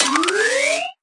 Media:RA_Jessie_Evo.wav UI音效 RA 在角色详情页面点击初级、经典和高手形态选项卡触发的音效